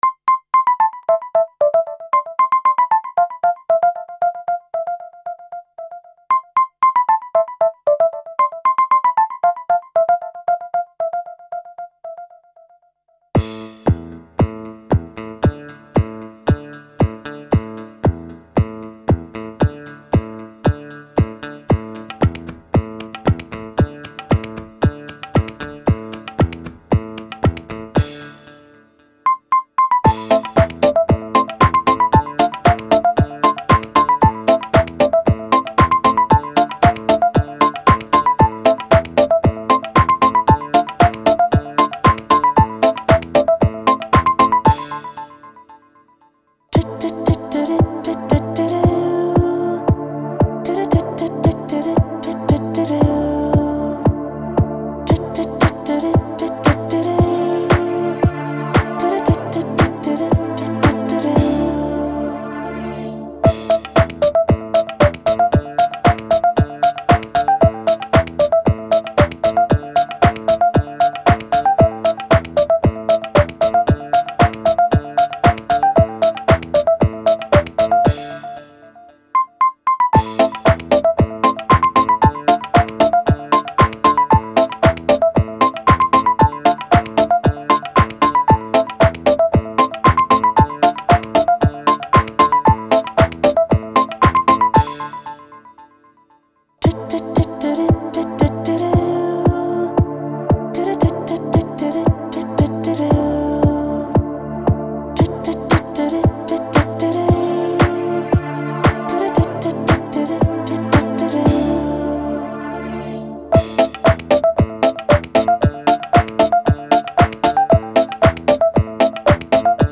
سبک آلبوم: پاپ